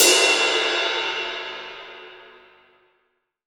Index of /90_sSampleCDs/AKAI S6000 CD-ROM - Volume 3/Ride_Cymbal1/20INCH_ZIL_RIDE